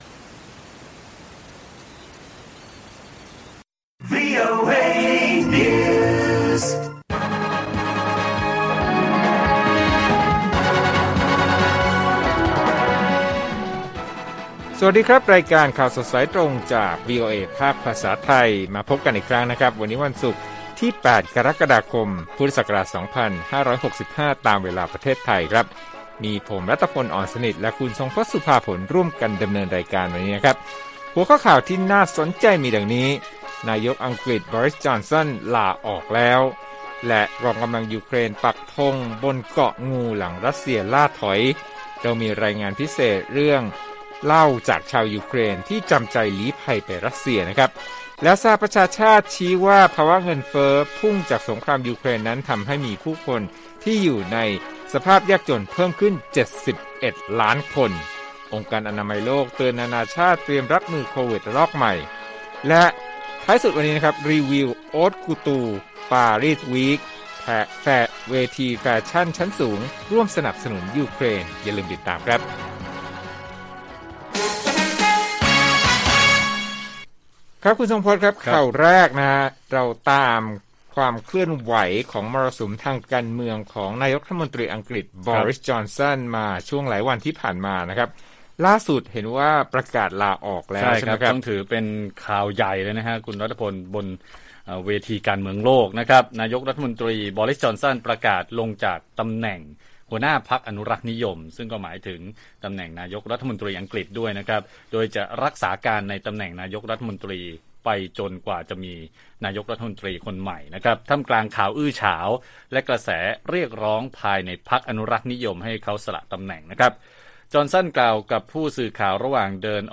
ข่าวสดสายตรงจากวีโอเอไทย 8:30–9:00 น. วันที่ 8 ก.ค. 65